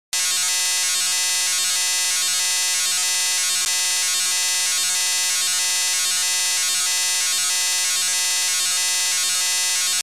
TACS_missing_traffic_sample.mp3